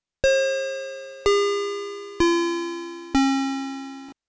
downchimes.wav